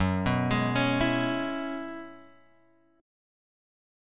F# 9th Suspended 4th